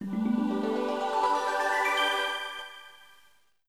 Sega NAOMI Startup Phase 2.wav